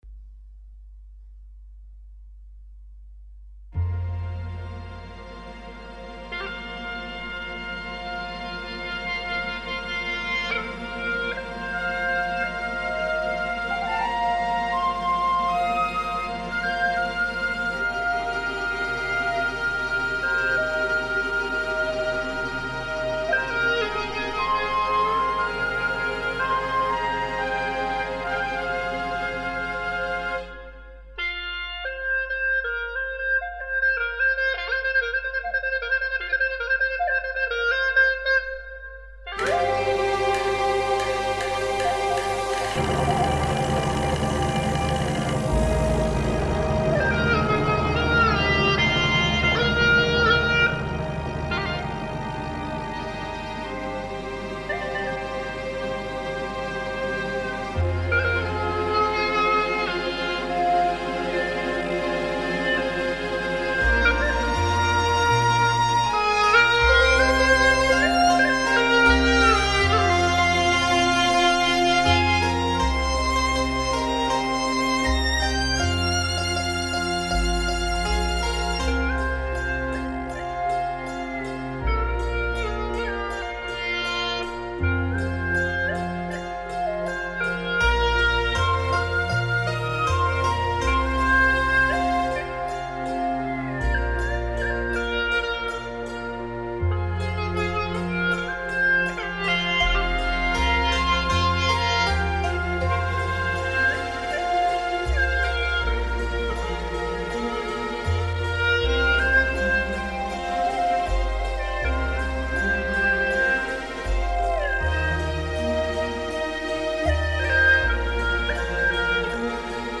调式 : 降E 曲类 : 独奏